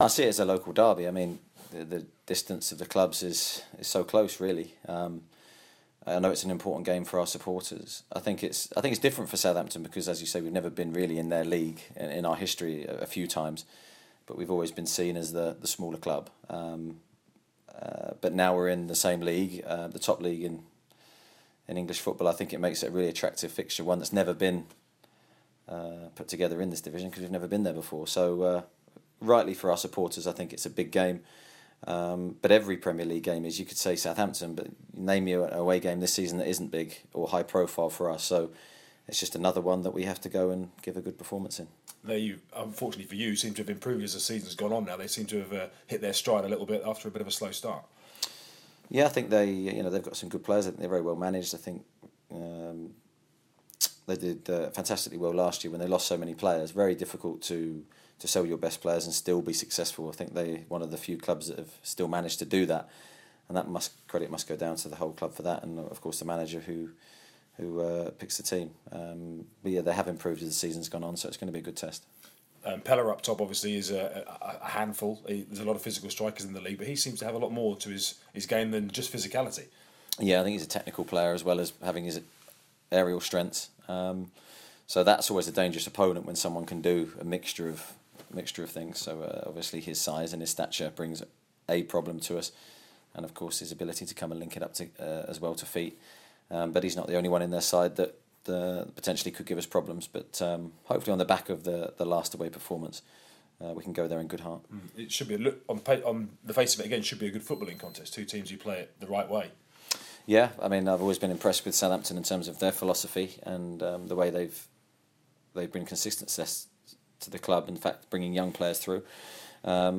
LISTEN; Eddie Howe says Saints game is a derby - preview interview